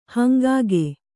♪ hangāge